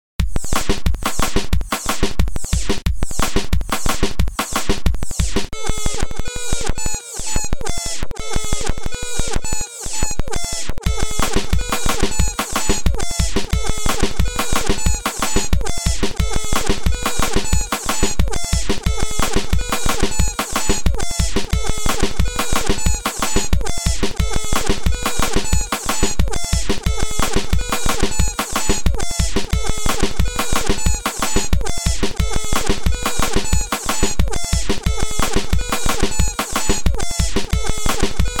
инструменты